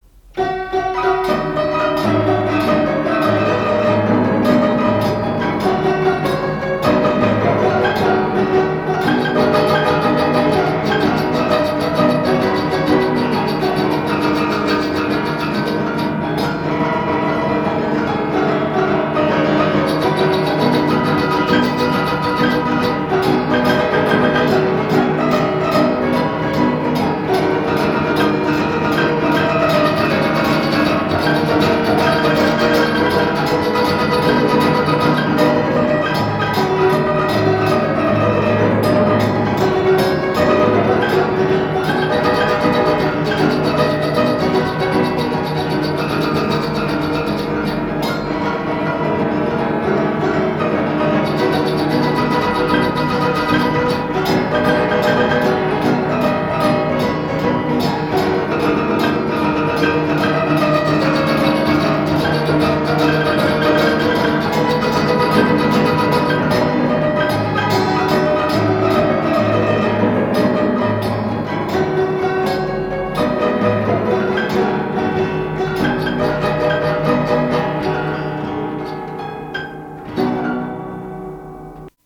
piano mécanique
Pièce musicale inédite